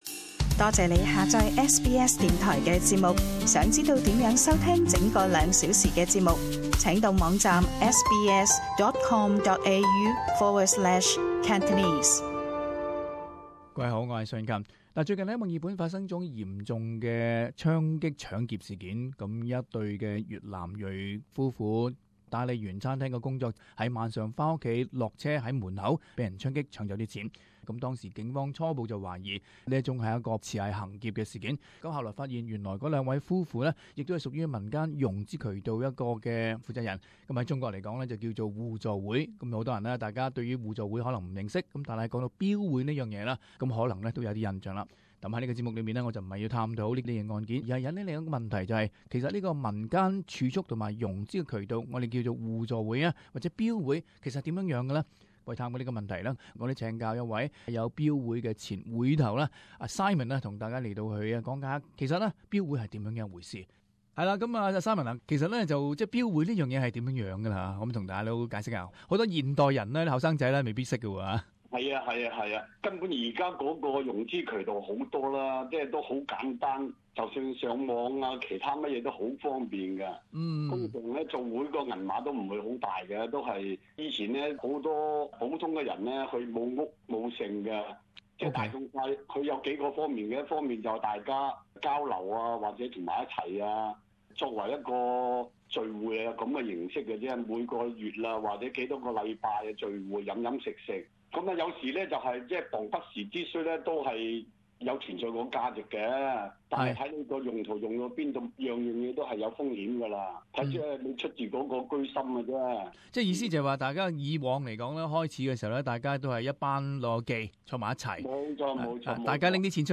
【社团专访】标会的方便与风险